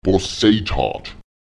Wird an ein yorlakesisches Wort, das mit w, y oder einem Vokal (a, á, e, i, o, ó, u, ú) endet, ein Suffix egal welcher Art angehängt, verlagert sich die Betonung auf die Silbe vor diesem Suffix.